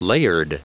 Prononciation du mot layered en anglais (fichier audio)
Prononciation du mot : layered